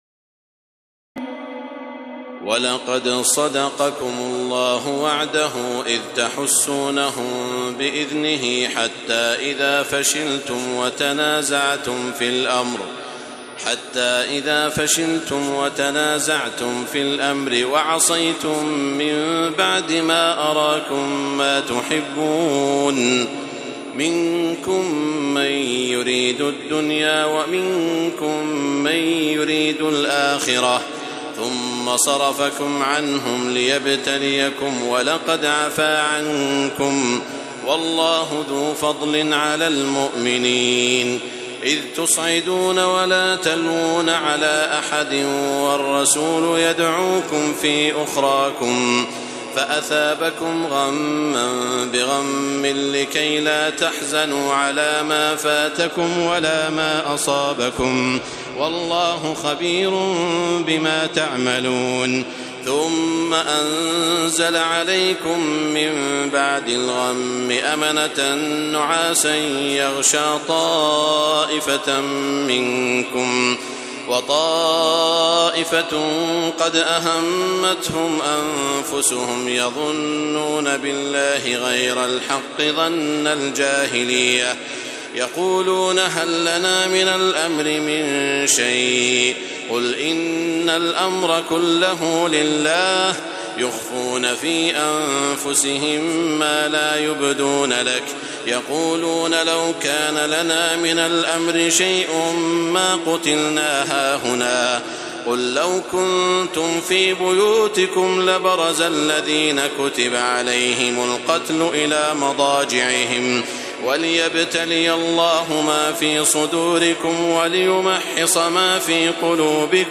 تراويح الليلة الرابعة رمضان 1432هـ من سورتي آل عمران (152-200) و النساء (1-18) Taraweeh 4 st night Ramadan 1432H from Surah Aal-i-Imraan and An-Nisaa > تراويح الحرم المكي عام 1432 🕋 > التراويح - تلاوات الحرمين